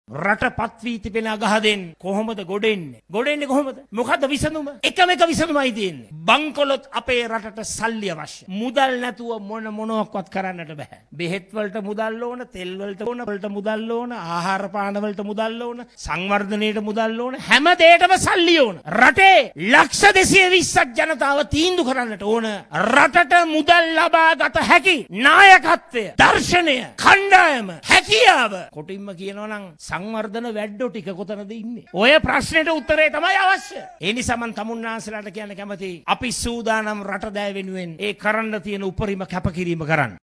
නිරුත්තර රටකට උත්තරය යන මැයෙන් මාතලේ රත්තොට ප්‍රදේශයේ පැවති ජන හමුවකට එක්වෙමින් සජිත් ප්‍රේමදාස මහතා මෙම අදහස් පළ කළා.